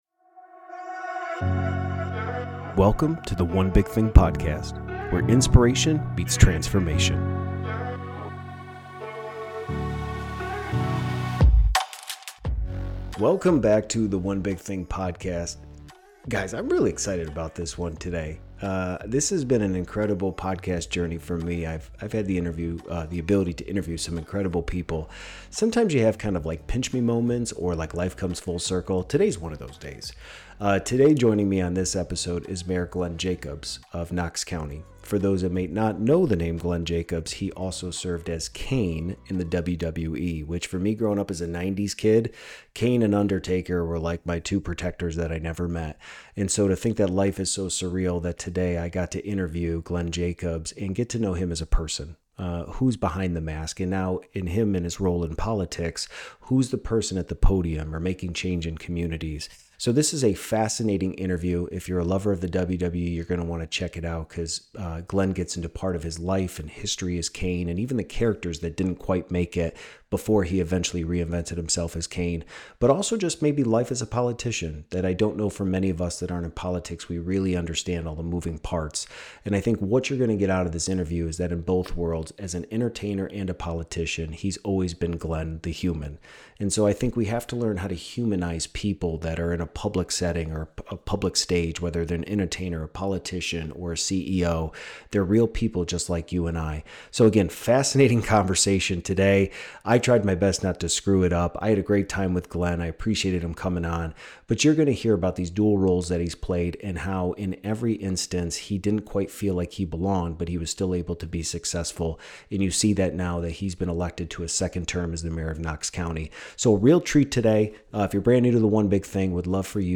We embark on a compelling conversation through his remarkable journey from the wrestling ring to public office.